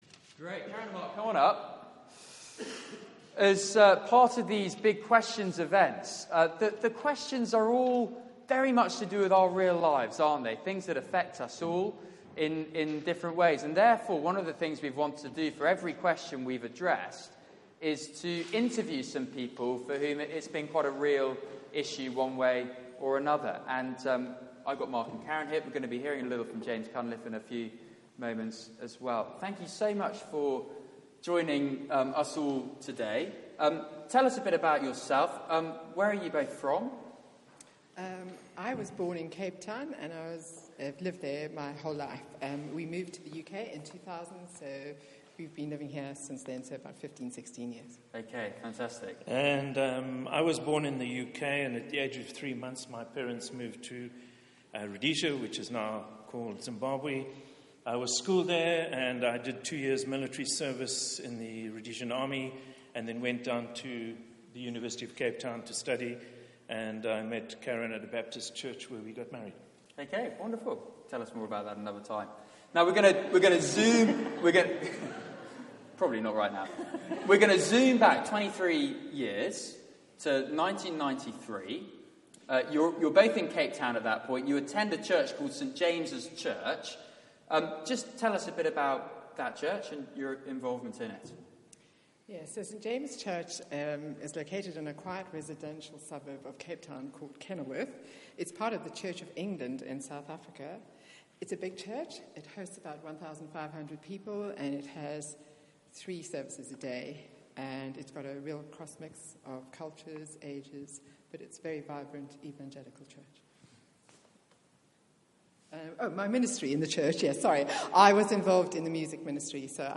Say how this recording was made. Media for 4pm Service on Sun 20th Mar 2016 16:00 Speaker